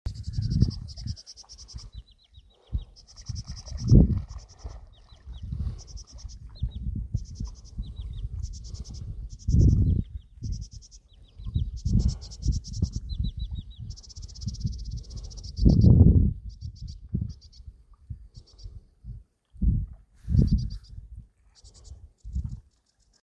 Putni -> Ķauķi ->
Ceru ķauķis, Acrocephalus schoenobaenus
Administratīvā teritorijaBurtnieku novads